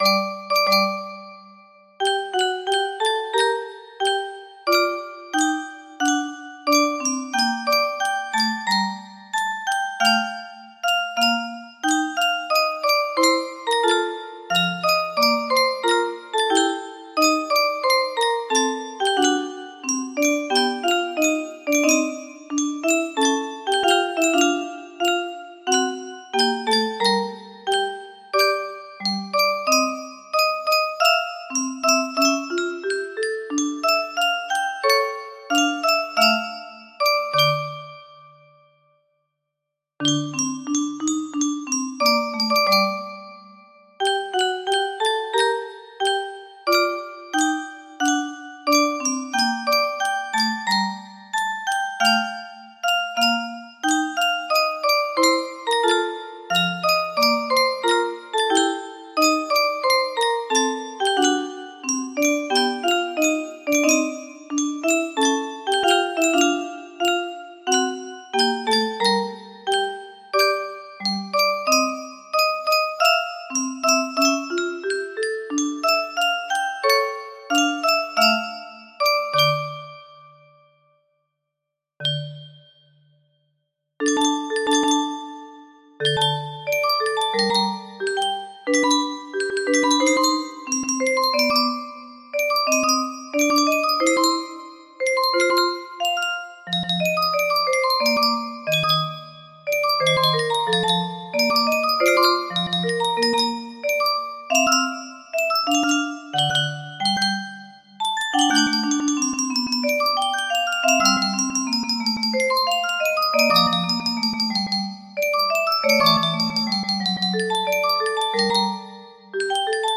Iombeau de Mr. Francisque Corbet music box melody
Full range 60